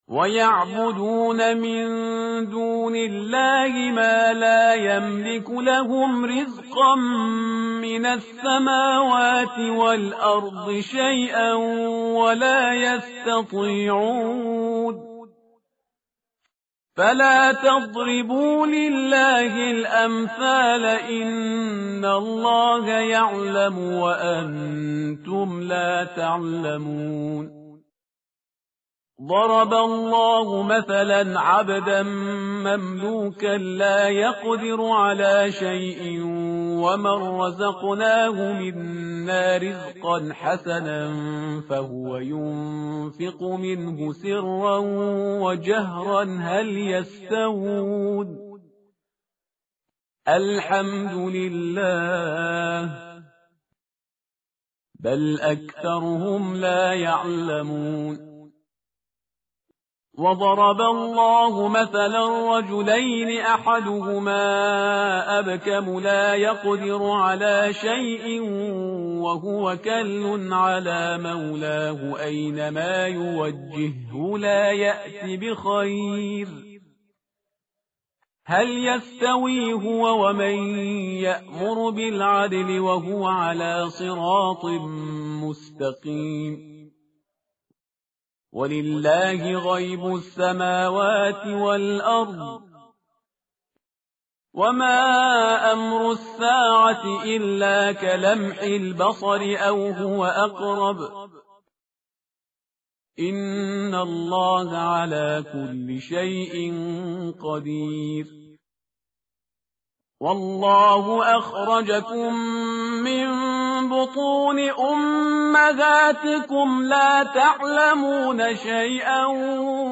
متن قرآن همراه باتلاوت قرآن و ترجمه
tartil_parhizgar_page_275.mp3